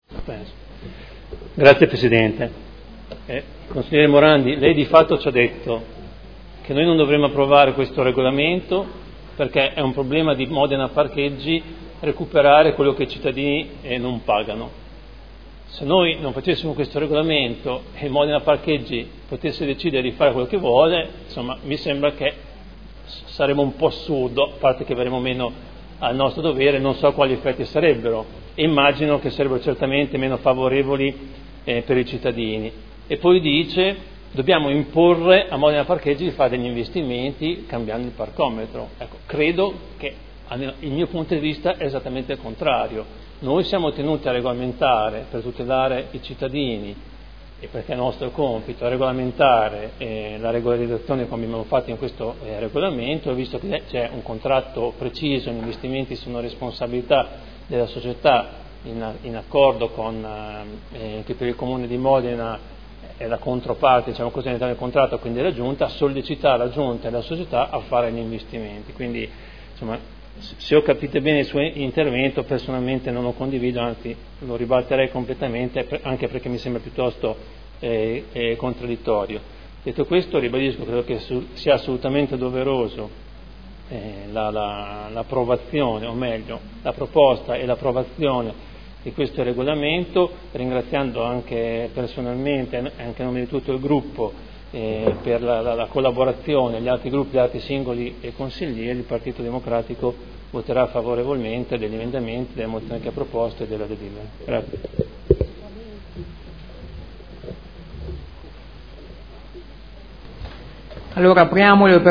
Seduta del 09/07/2015 Dichiarazione di voto. Approvazione del Regolamento per l’utilizzo delle aree di parcheggio a pagamento su strada.